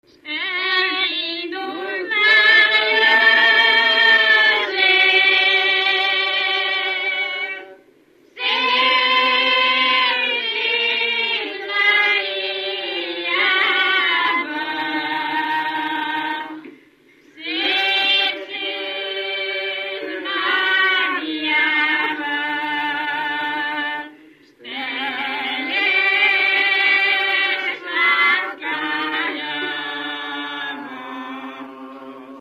Erdély - Kolozs vm. - Visa
Stílus: 5. Rákóczi dallamkör és fríg környezete
Szótagszám: 7.6.7.6
Kadencia: 5 (b3) 2 1